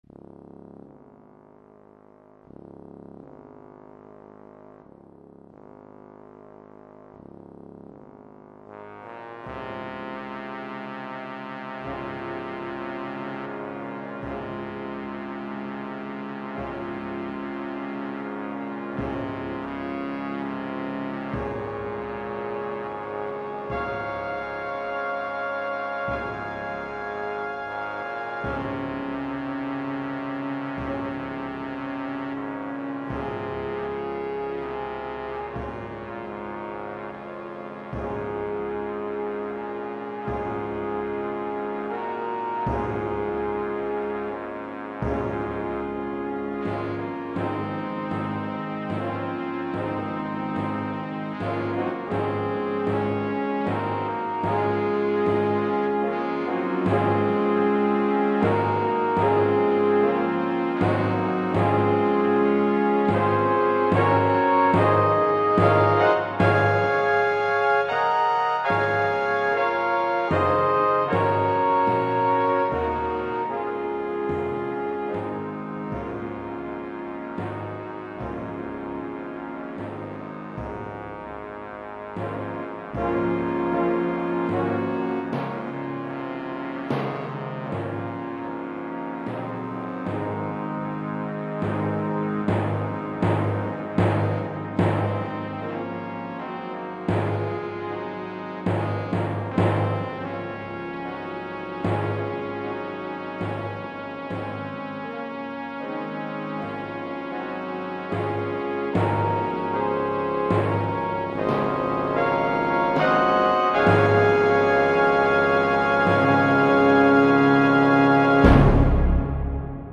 The third of four 'gumdrop' MIDI improvisations from 1999, newly converted to MP3 using Apple Quicktime.
gumdrop-state-fanfare.mp3